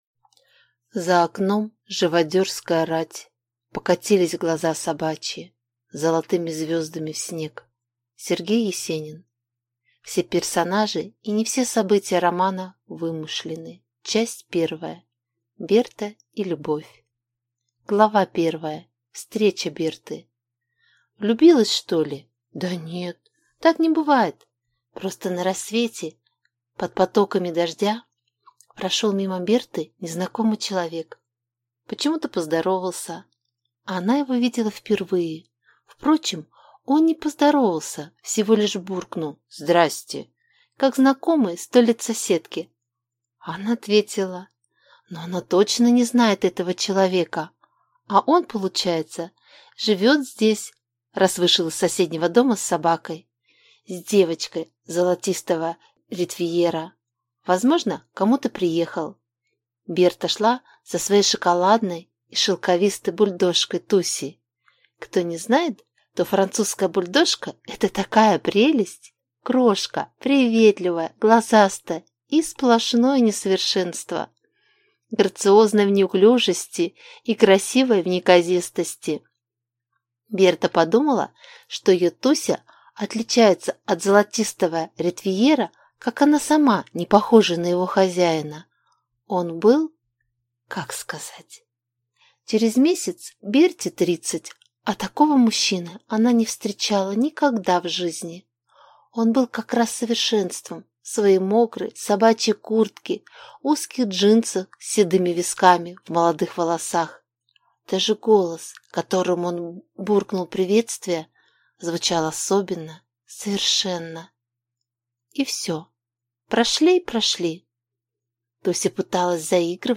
Прослушать фрагмент аудиокниги Плата за капельку счастья Евгения Михайлова Произведений: 28 Скачать бесплатно книгу Скачать в MP3 Вы скачиваете фрагмент книги, предоставленный издательством